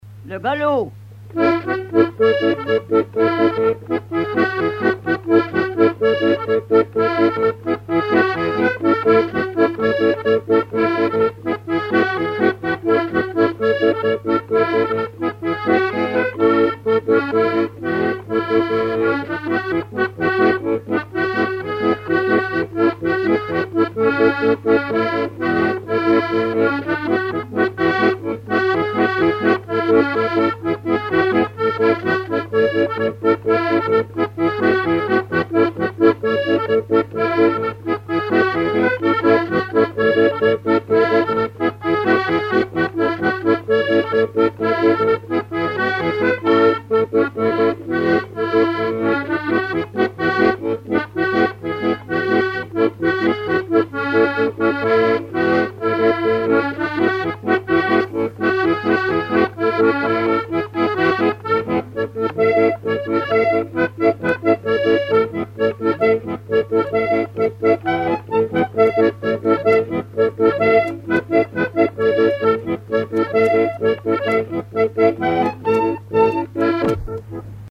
Mémoires et Patrimoines vivants - RaddO est une base de données d'archives iconographiques et sonores.
Quadrille - Le galop
danse : quadrille : galop
Pièce musicale inédite